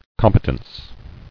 [com·pe·tence]